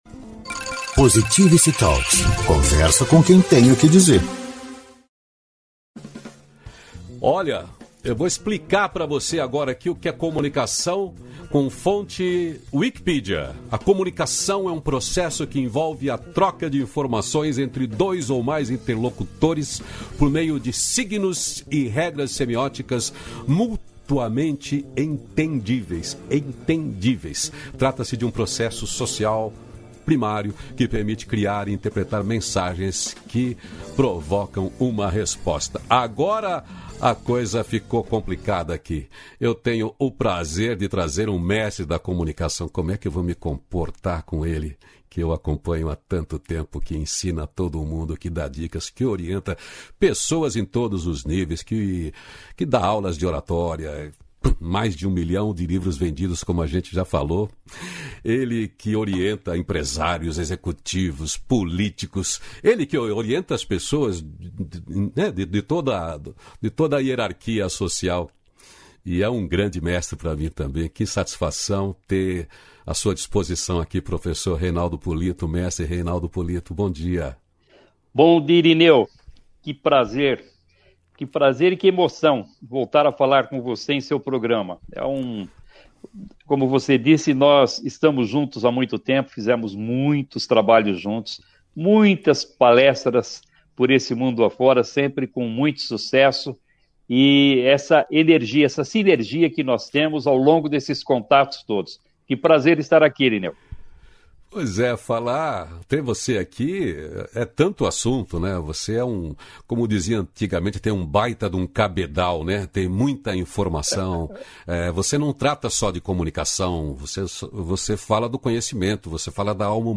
240-feliz-dia-novo-entrevista.mp3